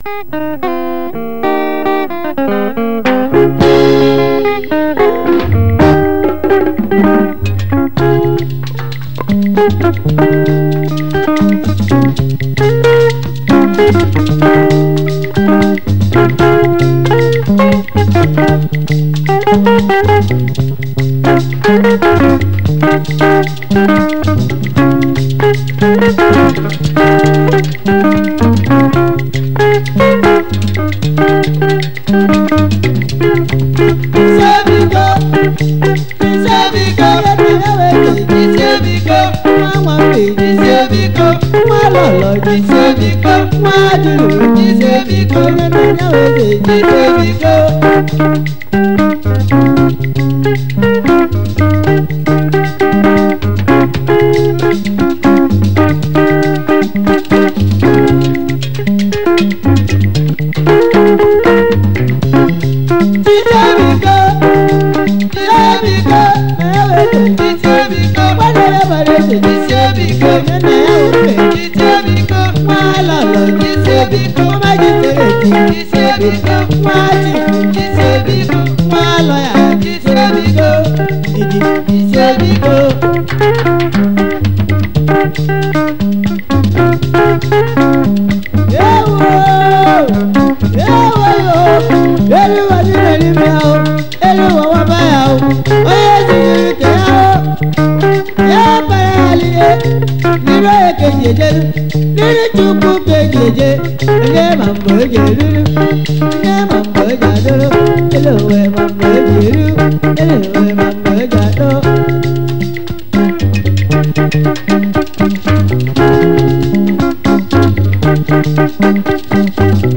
Gospel
was a Nigerian orchestra high life band from Eastern Nigeria